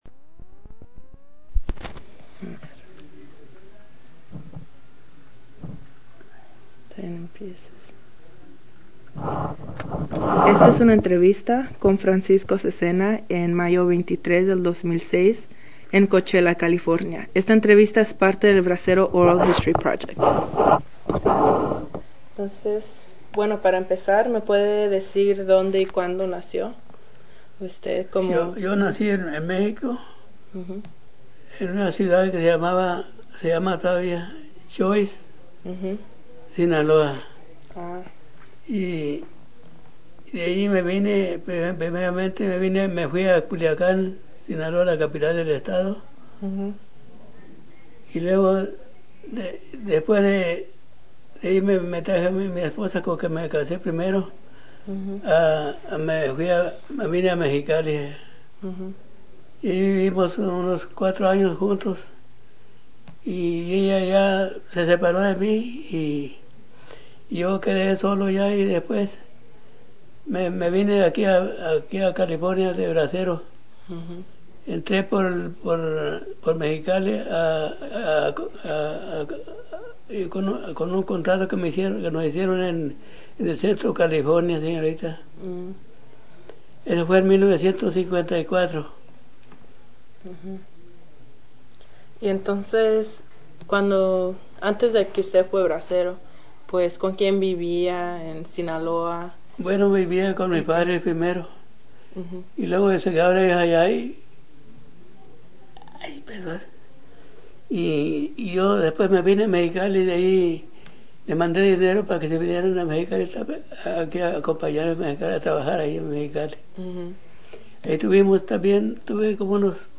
Original Format Mini disc
Location Coachella, CA